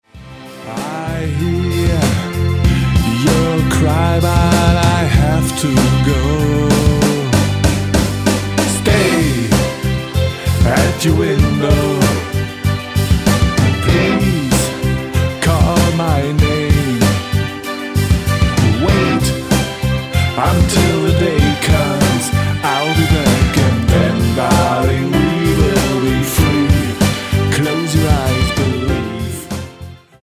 Text & Music, Guitars, Additional Keyboards, Programming
Vocals, Piano, Organ, Keyboards